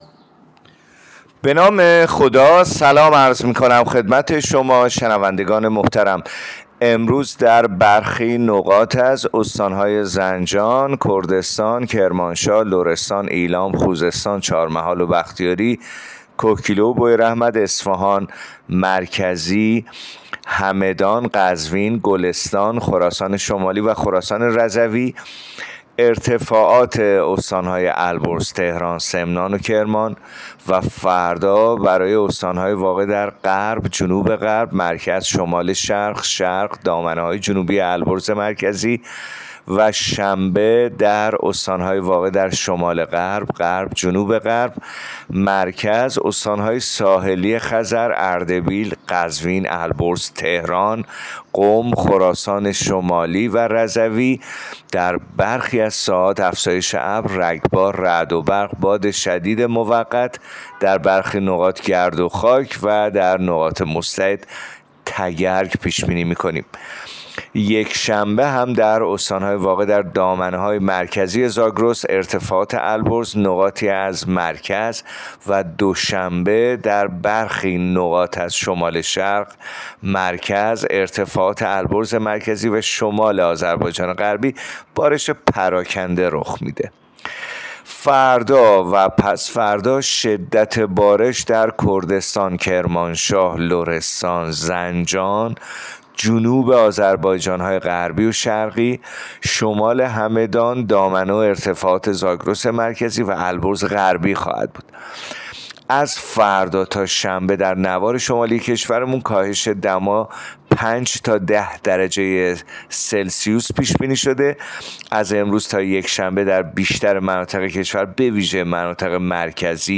گزارش رادیو اینترنتی پایگاه‌ خبری از آخرین وضعیت آب‌وهوای ۱۴ فروردین؛